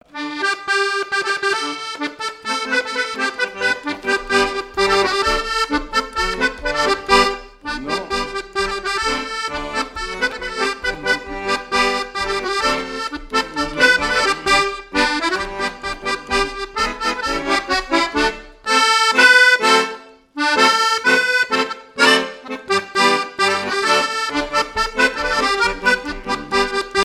danse : ronde
Genre énumérative
Témoignage sur la musique et des airs issus du Nouc'h
Catégorie Pièce musicale inédite